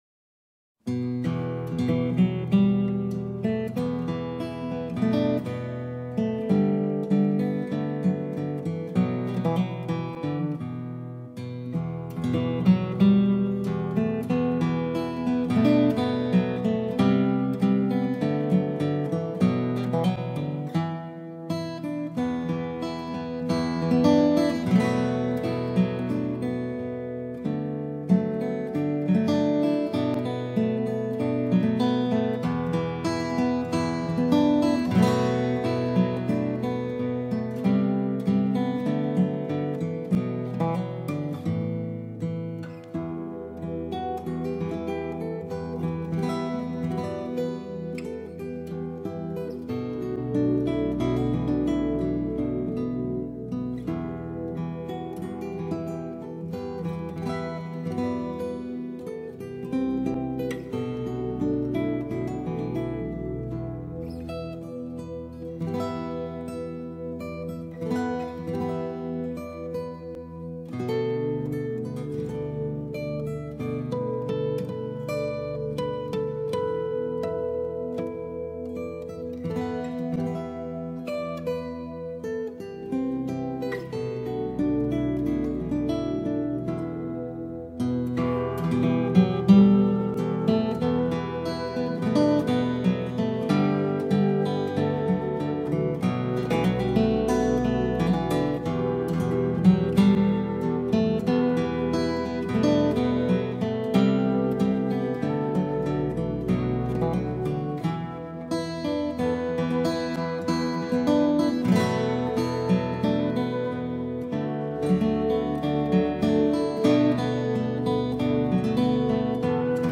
Down the Brae | Guitar